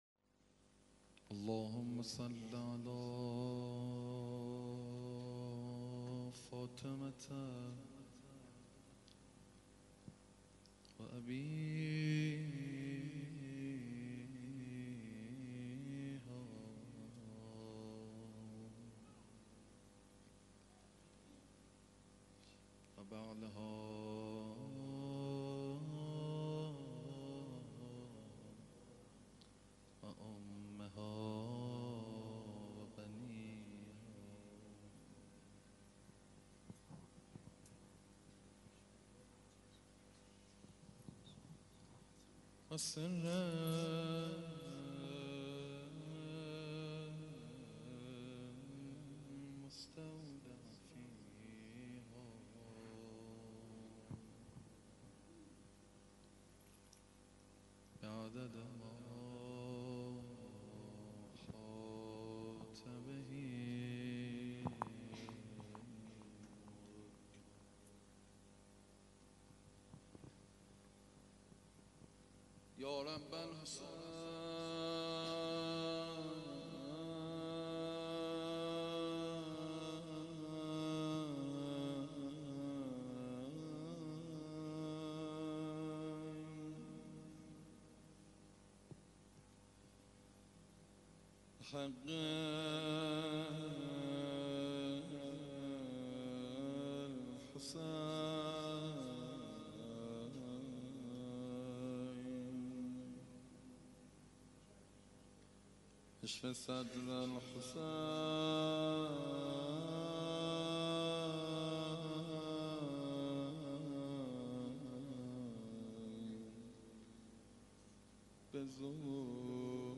1- روضه